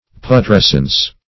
Putrescence \Pu*tres"cence\, n.